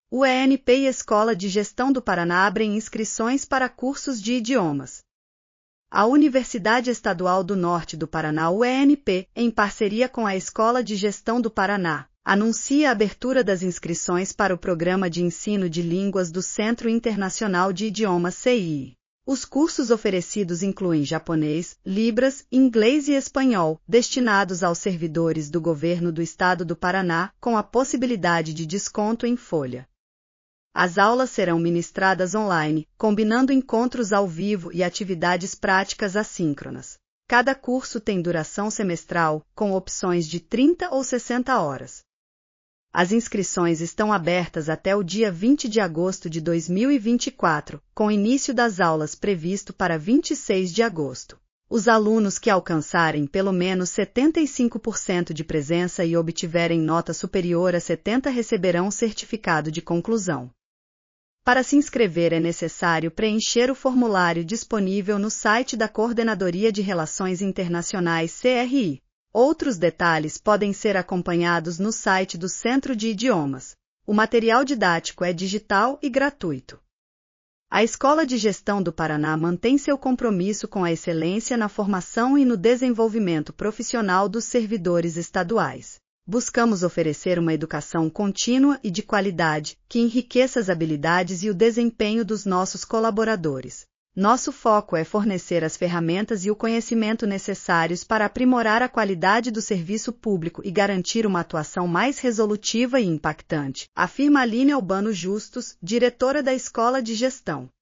audionoticia_uenp_e_egp_idiomas.mp3